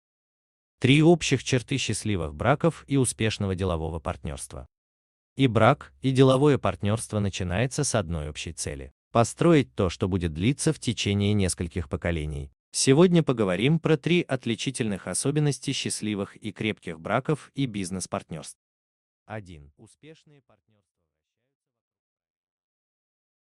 Аудиокнига Что общего между счастливыми браками и успешным деловым партнерством?